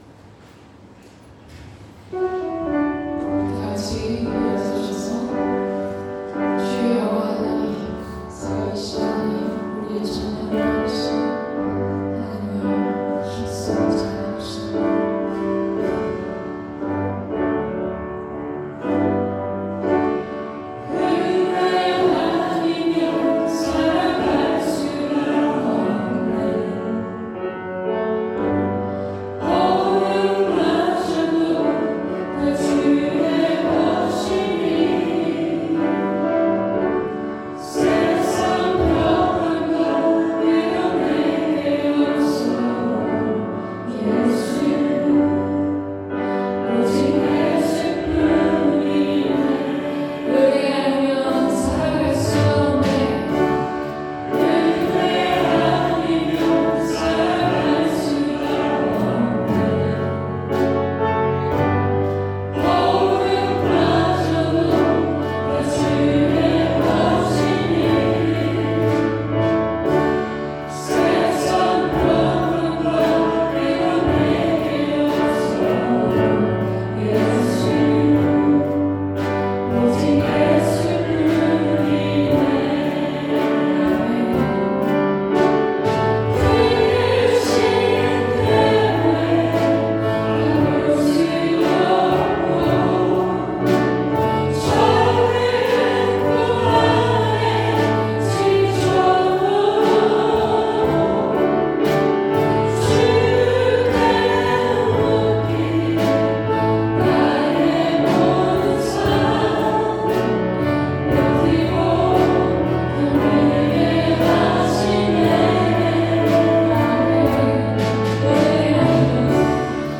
2026년 02월 15일 주일찬양